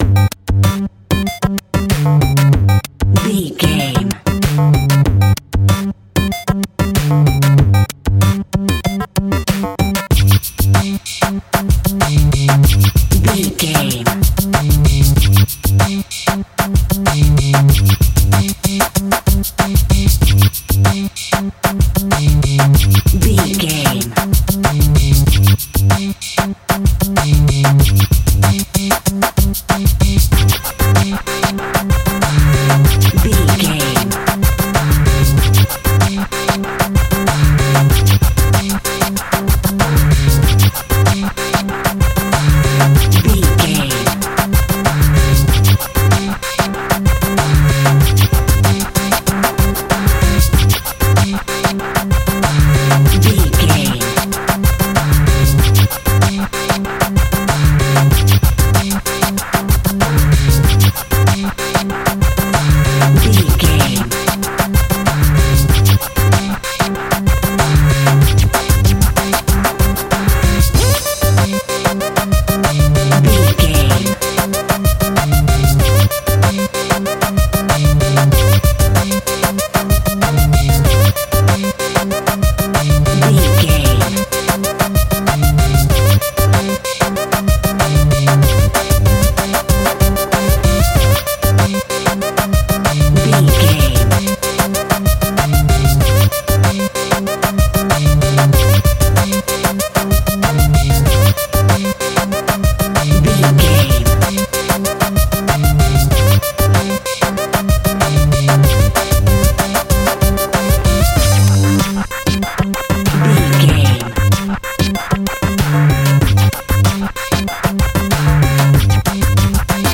Aeolian/Minor
hip hop
hip hop instrumentals
downtempo
synth lead
synth bass
synth drums
turntables